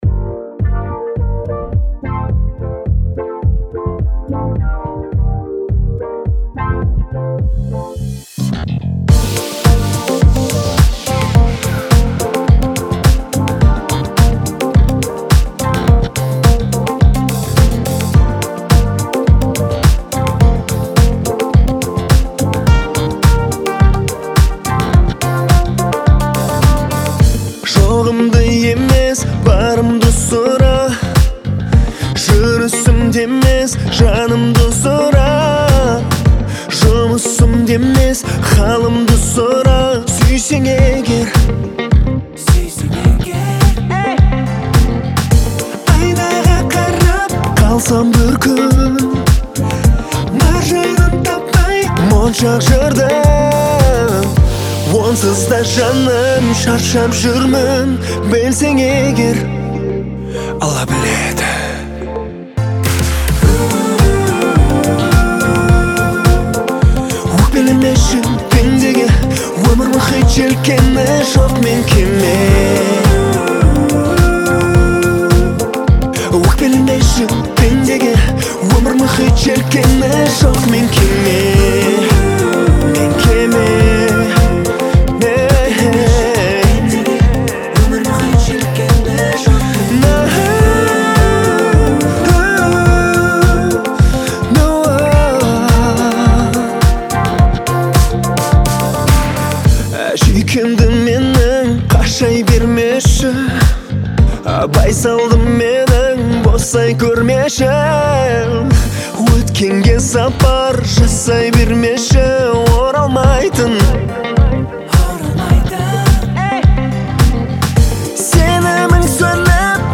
отличается искренностью и эмоциональностью